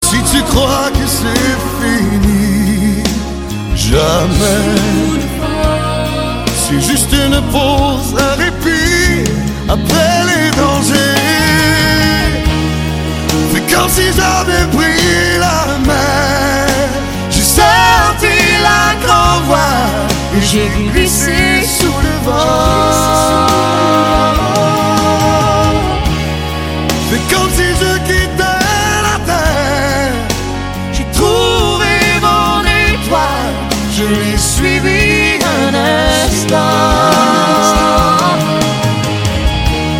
дуэт